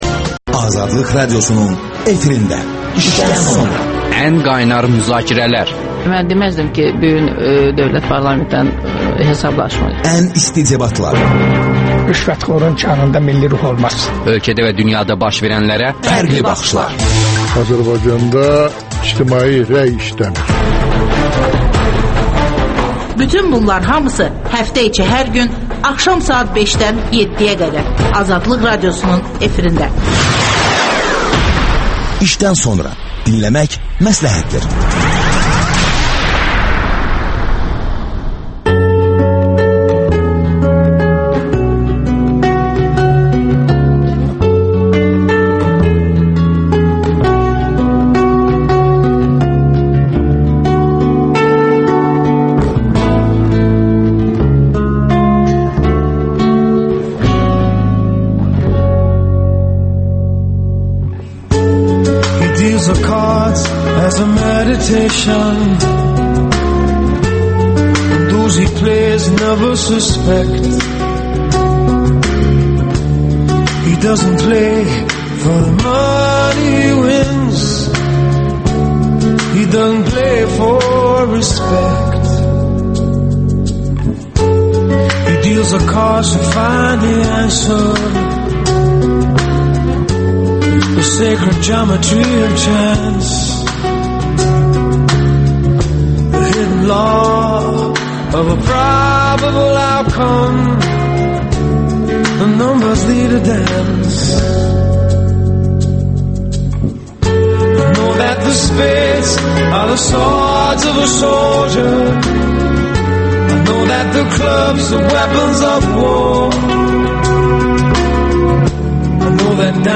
İşdən sonra - Rəhim Qazıyevlə söhbət